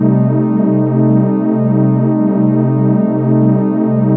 TENSION04.-L.wav